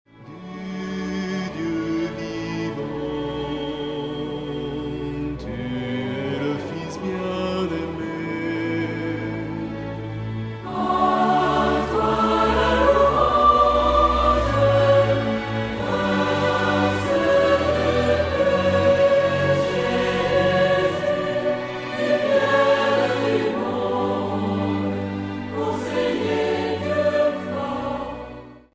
Chants d'adoration